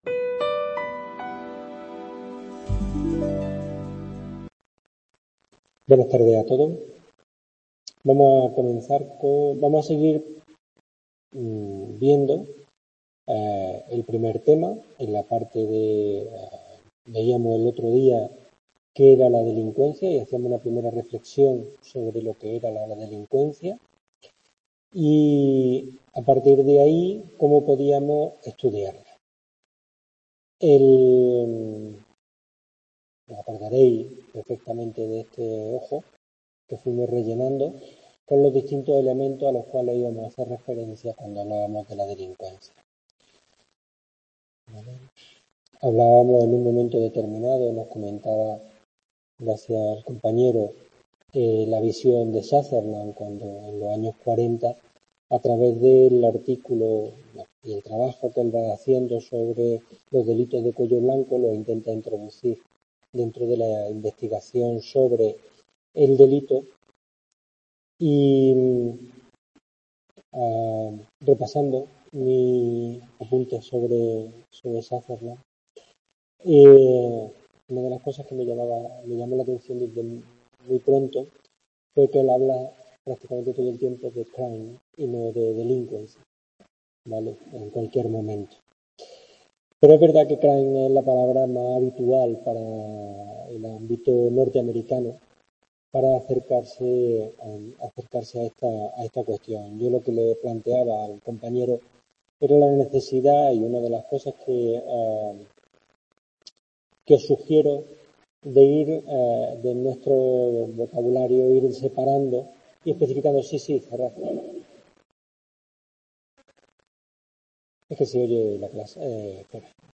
Description Videoclase sobre qué es la delincuencia, cómo podemos comprenderla como propiedad sistémica, y cómo podemos estudiarla.